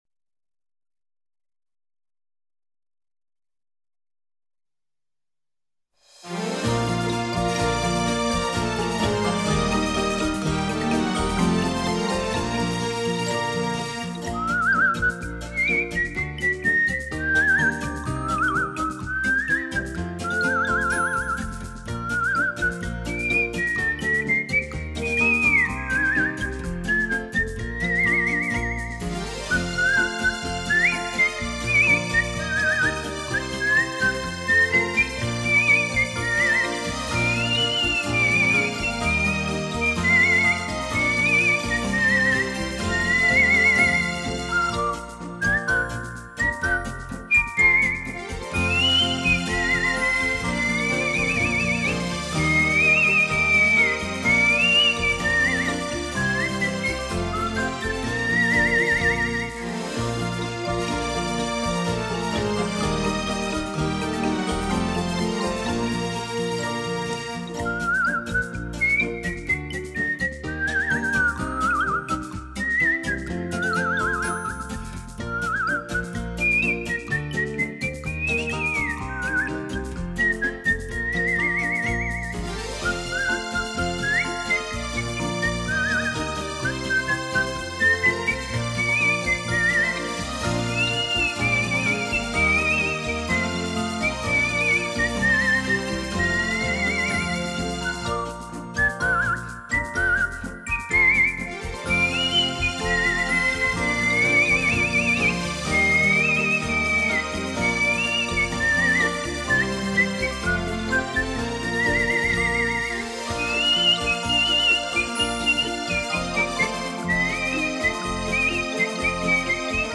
歌曲洋溢着欢乐的情绪。 　　歌曲是降A大调，2/4拍，用较快的速度演唱，歌曲结构为二部曲式。
口哨